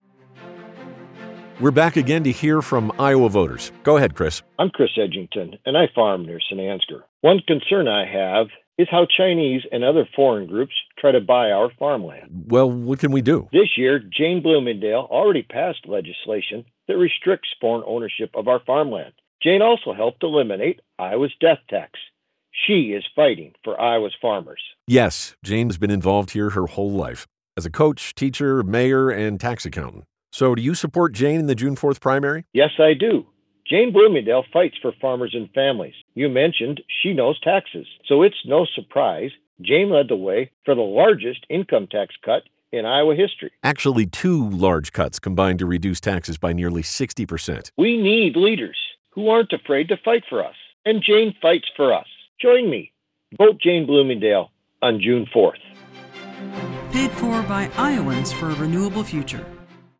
radio spots promoting the incumbent.